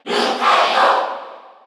File:Lucario Cheer Korean SSBU.ogg
Category: Crowd cheers (SSBU) You cannot overwrite this file.
Lucario_Cheer_Korean_SSBU.ogg.mp3